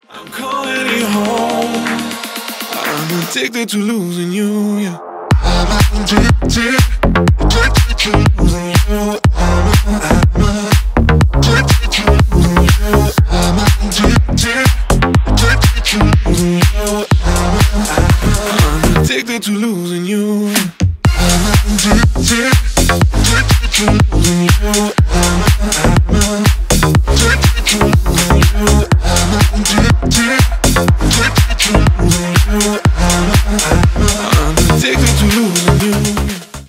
Танцевальные
клубные